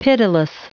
Prononciation du mot pitiless en anglais (fichier audio)
Prononciation du mot : pitiless